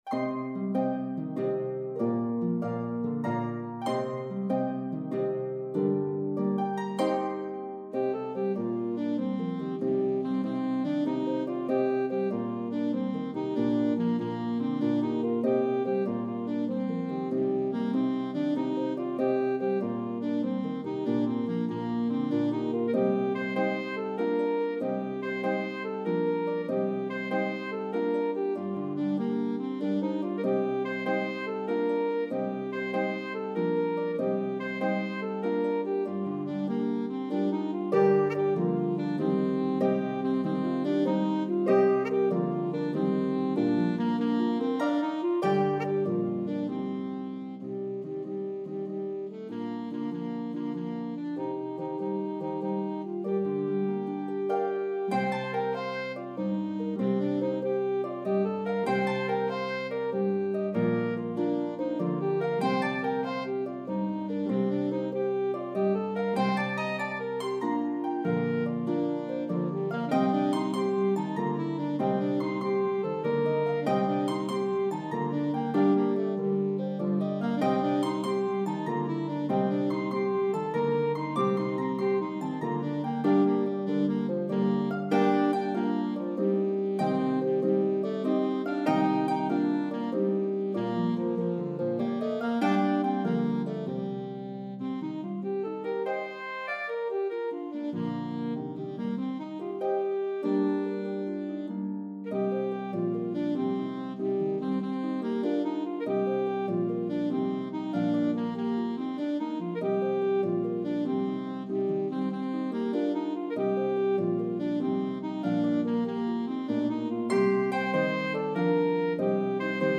One of the most energetic Step Dances in Slip Jig form.